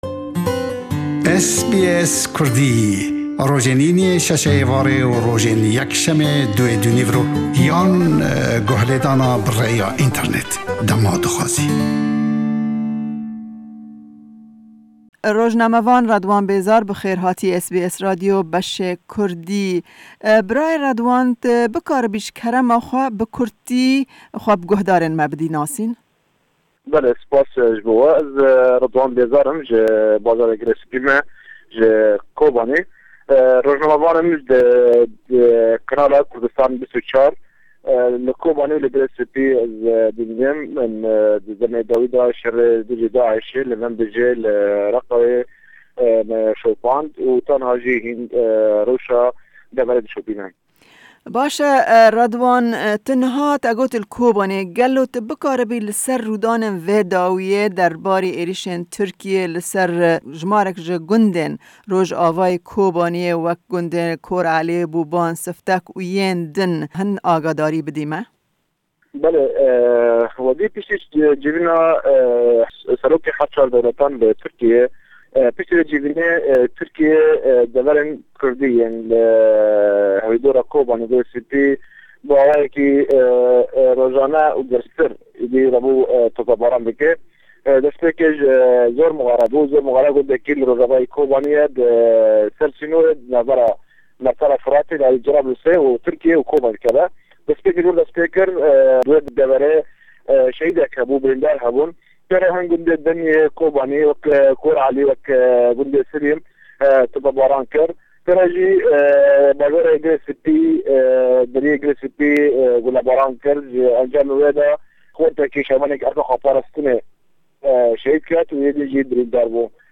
Hevpeyvîneke taybete